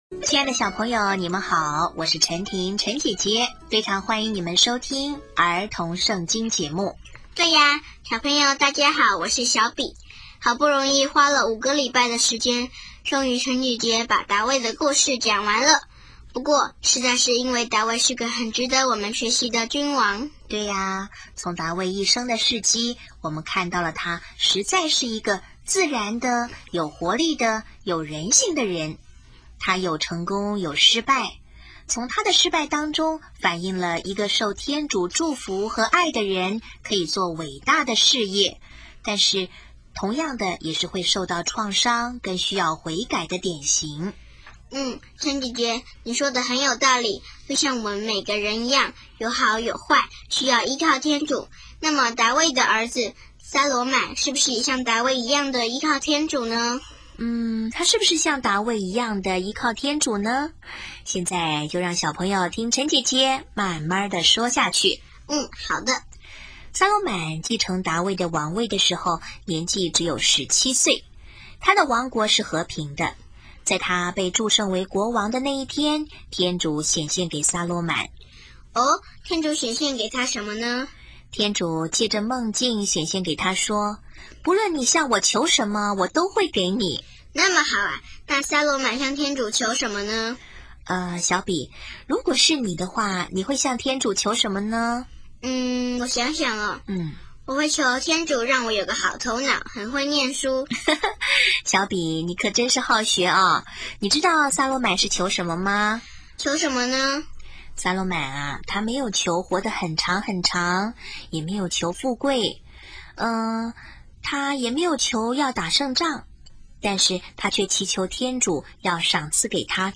【儿童圣经故事】29|撒罗满(一)智慧传世